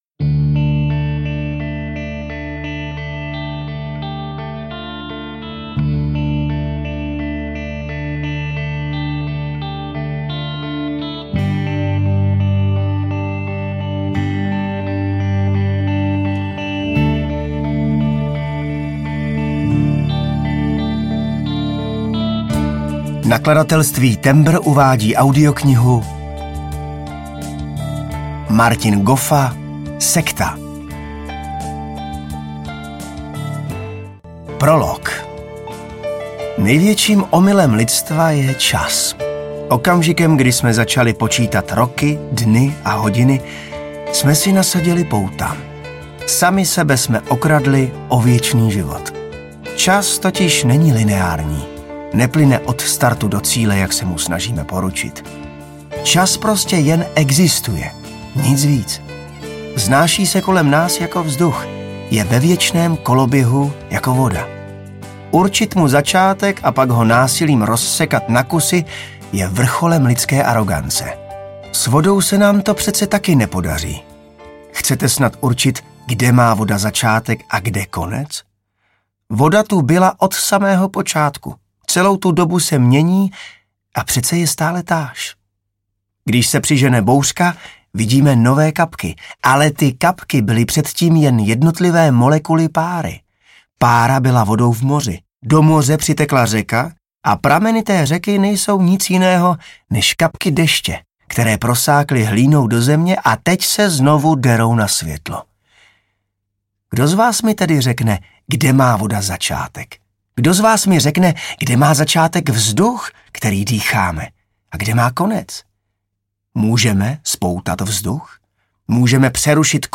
Sekta audiokniha
Ukázka z knihy
• InterpretJan Maxián